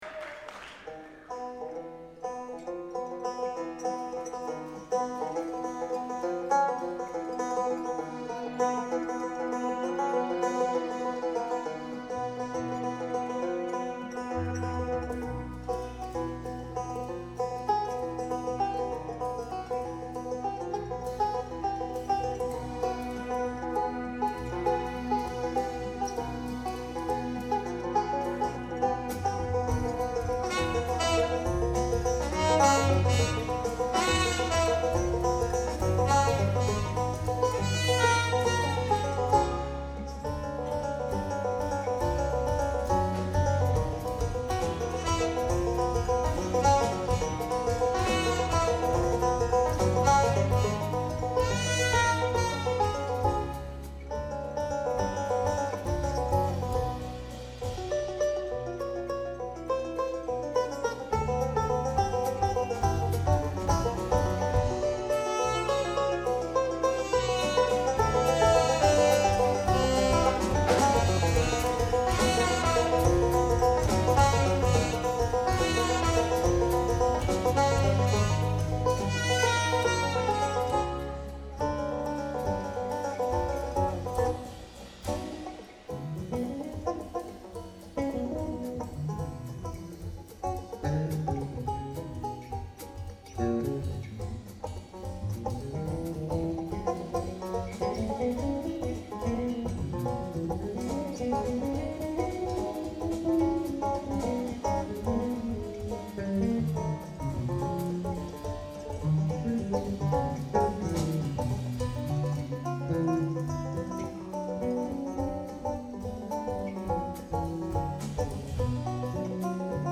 March 16, 2006 – Kentucky Theater, Lexington, KY